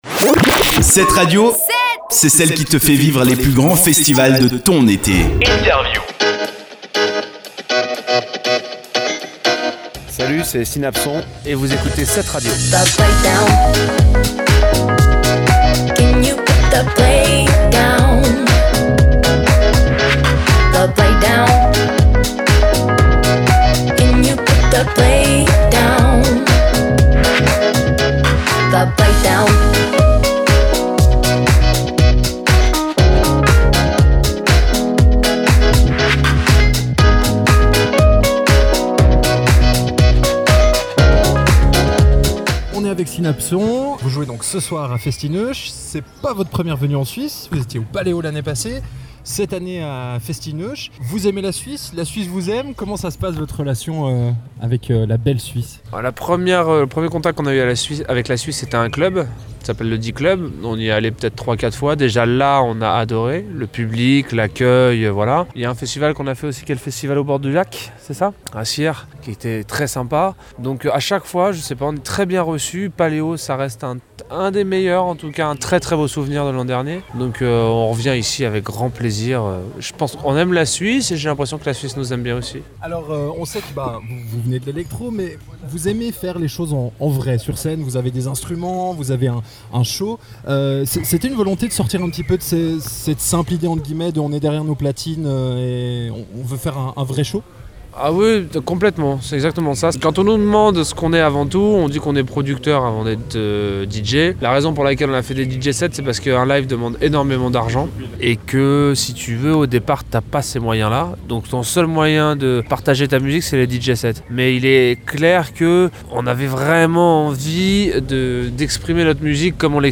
Synapson, Festi’neuch 2017 (1ère partie)
INTERVIEW-SYNAPSON-01.mp3